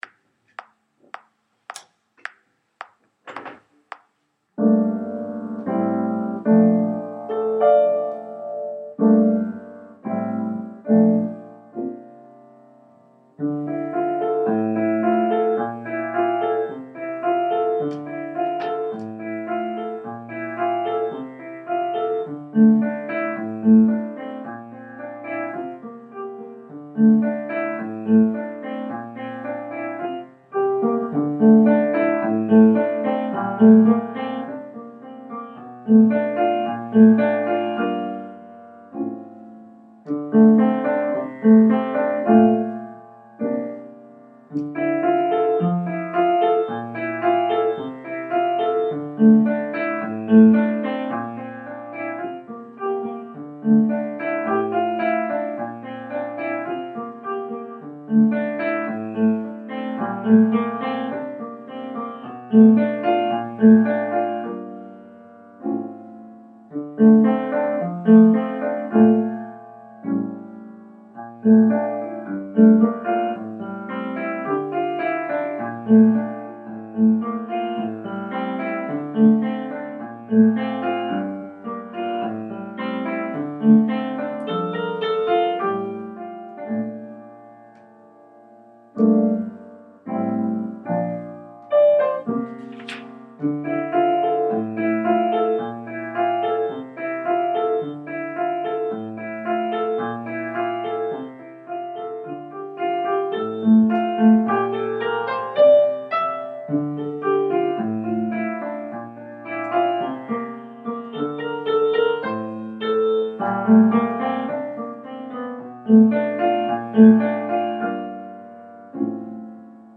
Don't Look Back Piano Part